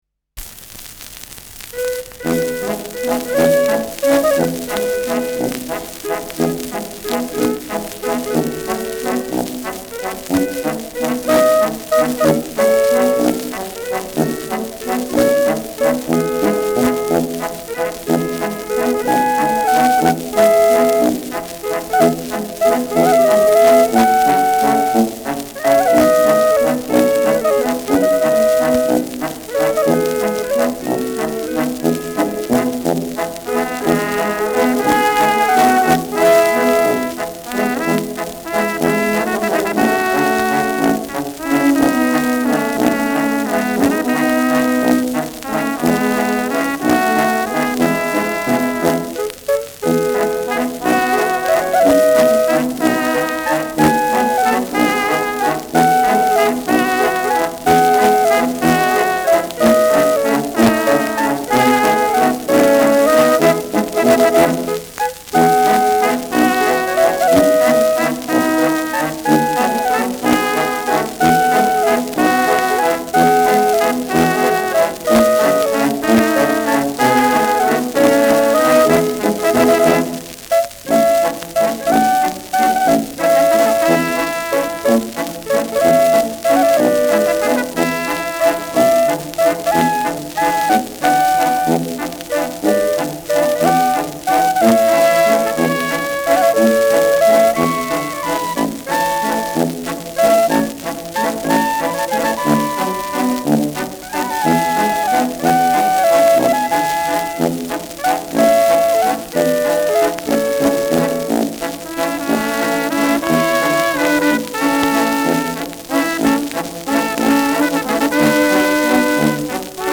Schellackplatte
[New York] (Aufnahmeort)